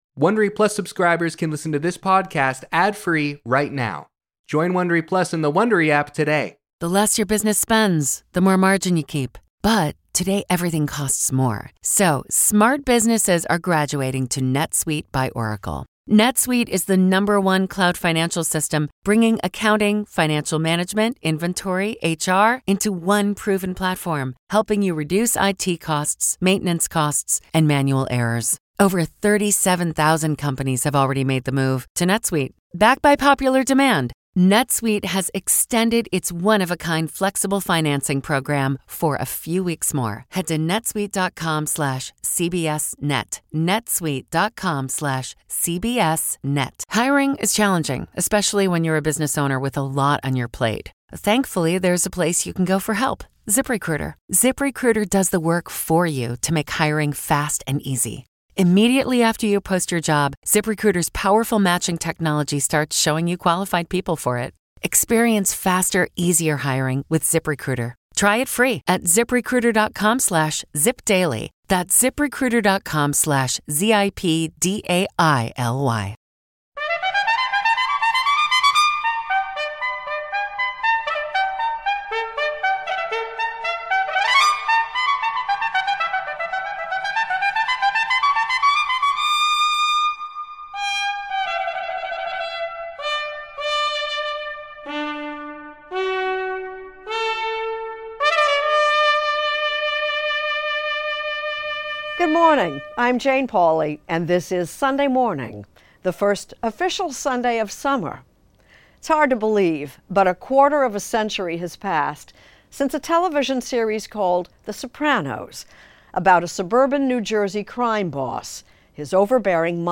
Hosted by Jane Pauley. In our cover story, Anthony Mason looks back at the series that changed television, "The Sopranos."